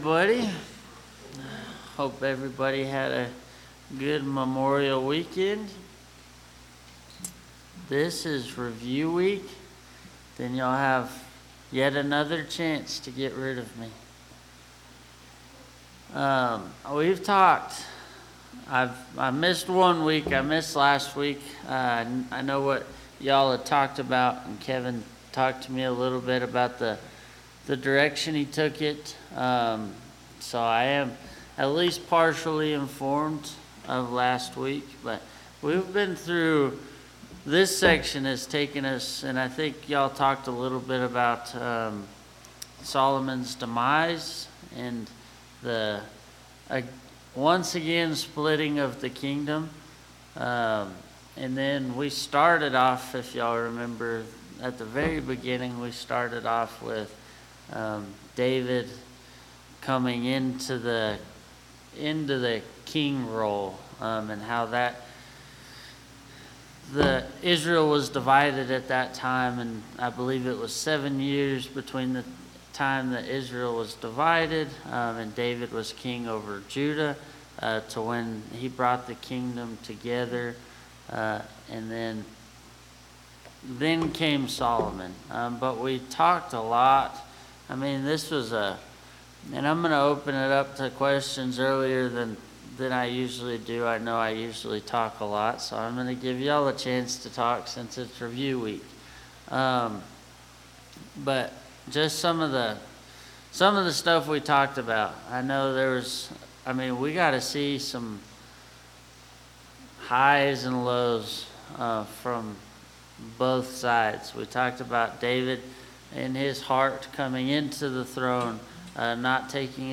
Bible Class 06/01/2025 - Bayfield church of Christ